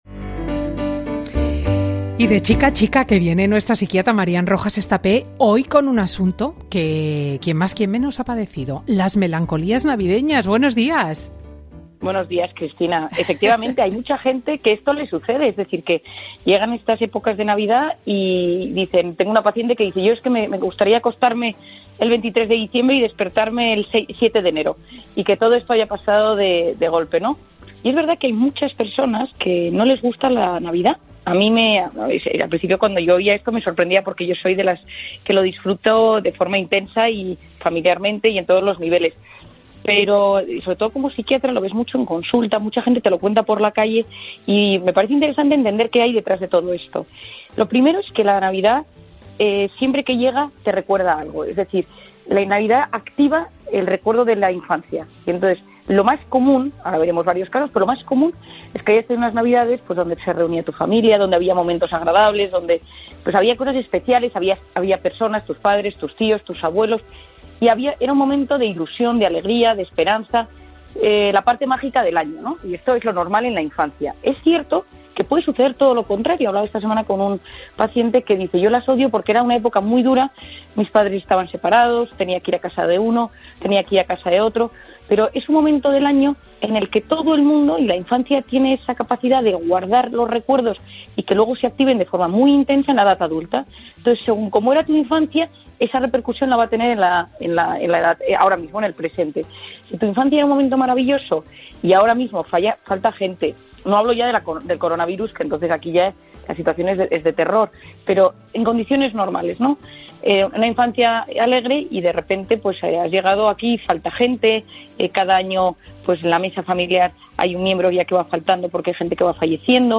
La psiquiatra da en Fin de Semana con Cristina consejos para las personas que peor lo pueden estar pasando en unas fechas que deberían ser de alegría pero pueden provocar tristeza